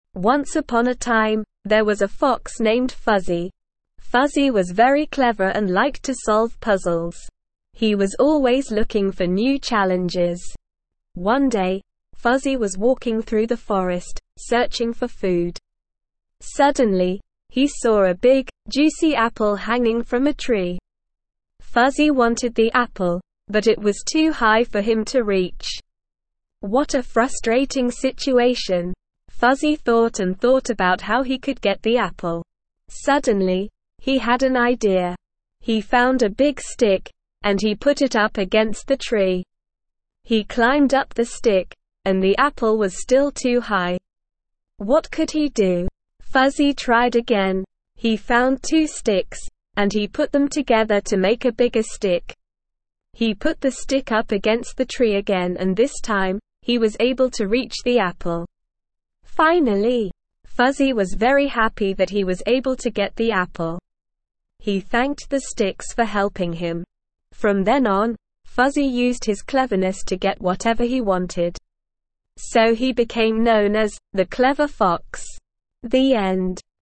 Slow
ESL-Short-Stories-for-Kids-SLOW-reading-The-Clever-Fox.mp3